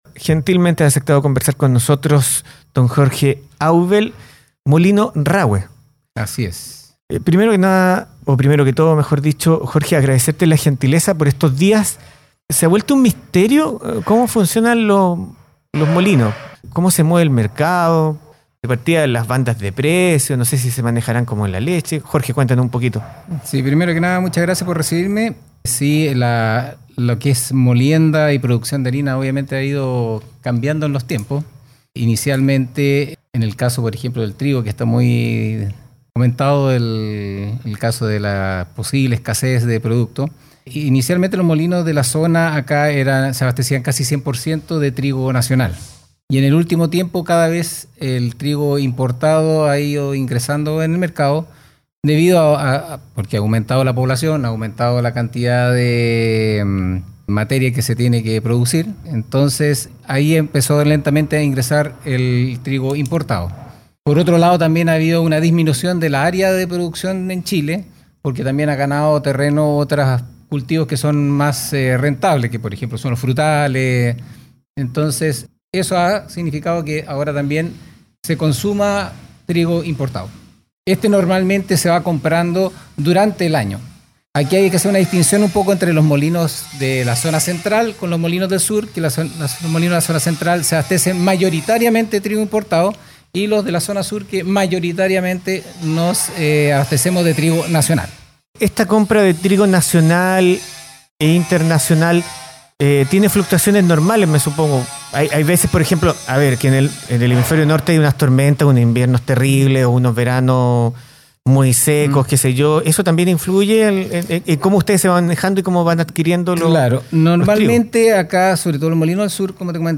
¿Escasez de harina? Le preguntamos a un molinero